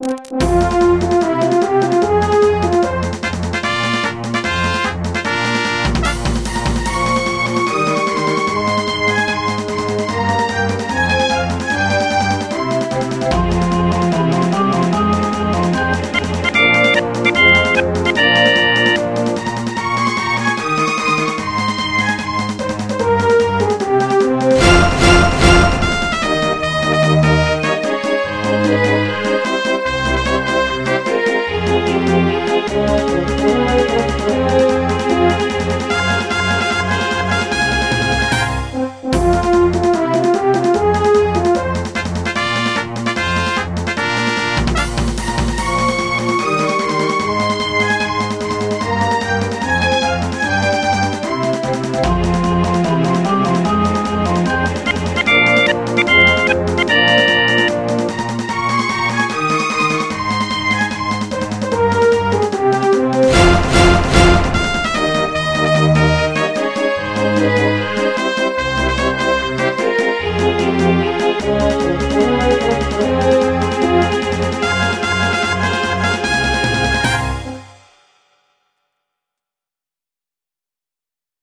MIDI 14.06 KB MP3